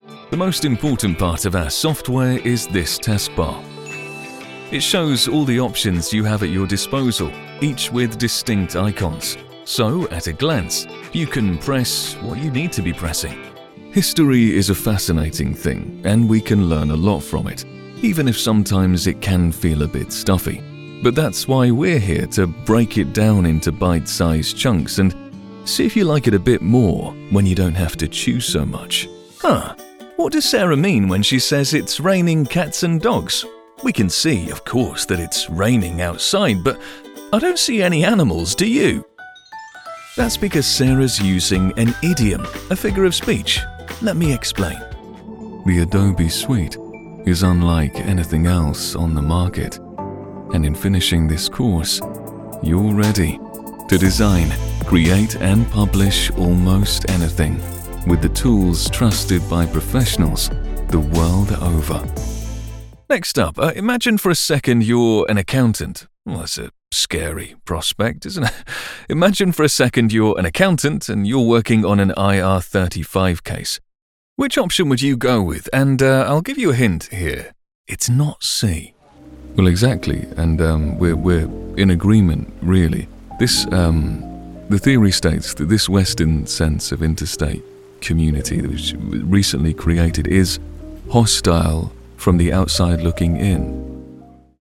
Male
English (British)
His voice puts the listener at ease, while keeping a confidence that doesn't patronise.
E-Learning
Words that describe my voice are warm, down-to-earth, relatable.
All our voice actors have professional broadcast quality recording studios.